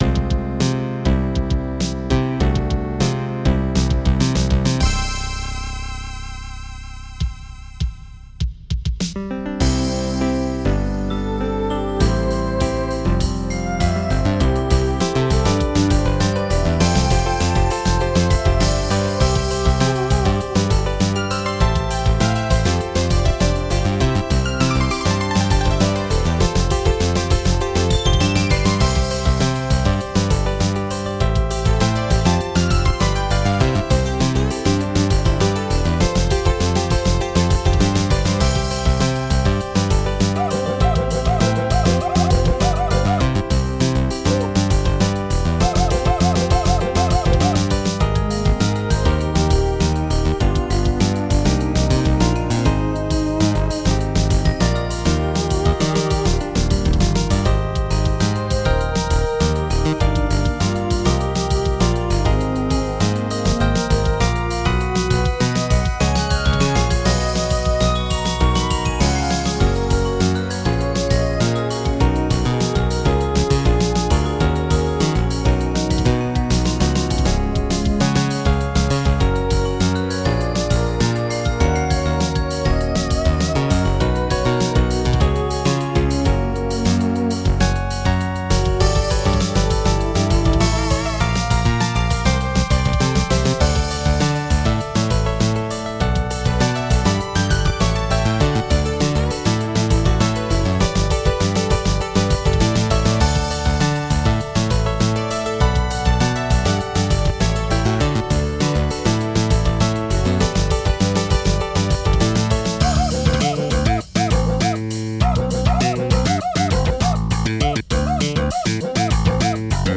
I made it using OpenMPT and the Munt MT-32 VST instrument.
SYNTHESIZER MUSIC; EXPERIMENTAL MUSIC